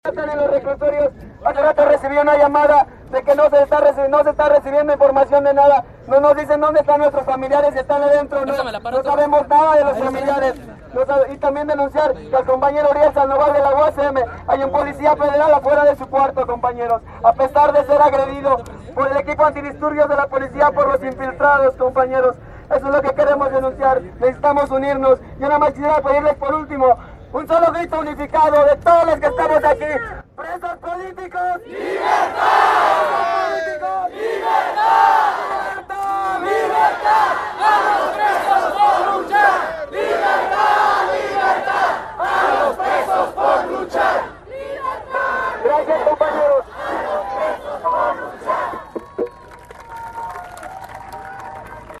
Durante el mitin en el zócalo se vivía un ambiente de tranquilidad, silencio y atención a las palabras emitidas a través del megáfono.Los presentes escuchaban con atención sentados desde sus lugares en la plancha del Zócalo.